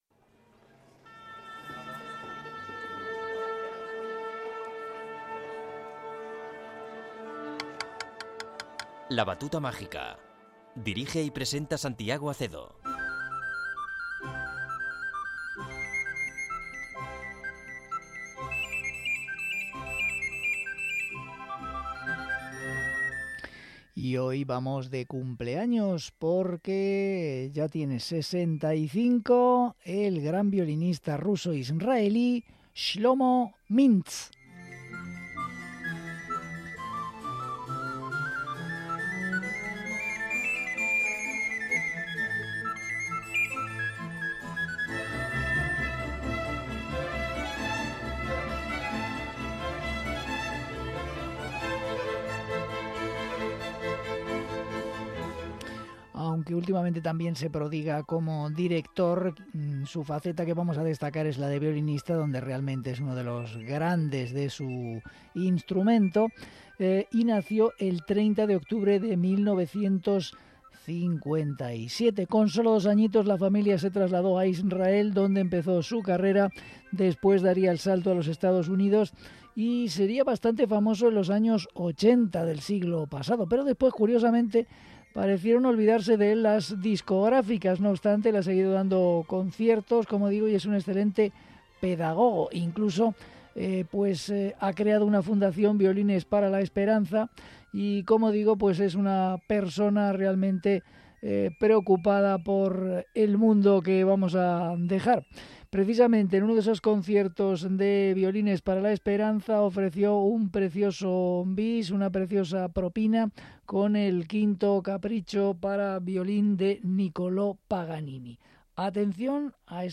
violinista